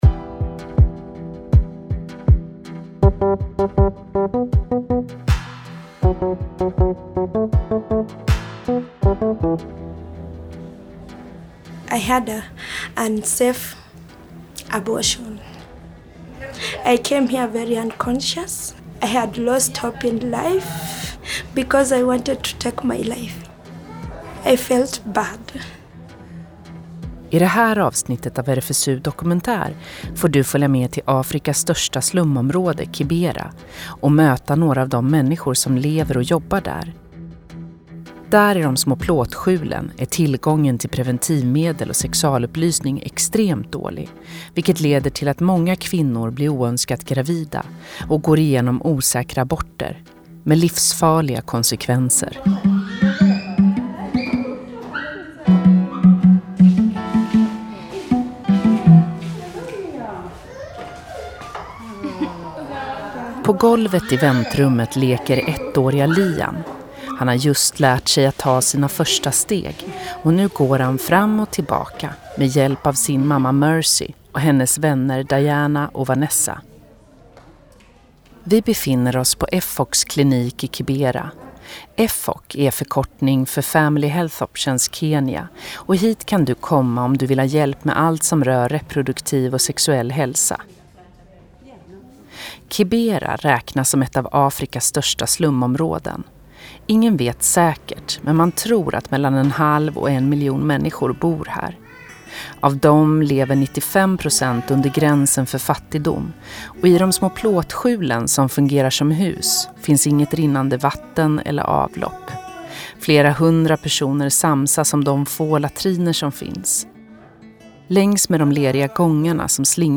Osäkra aborter RFSU dokumentär Ett piller i plåtskjulet Hastighet 0.5x 1x 1.25x 1.5x 2x Bakåt Spela Framåt Dela?